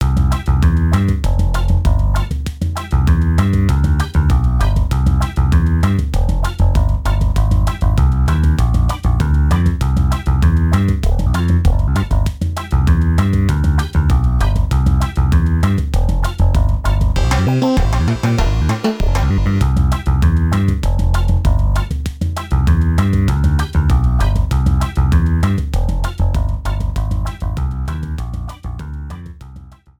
Cropped to 30 seconds, fade out added
Fair use music sample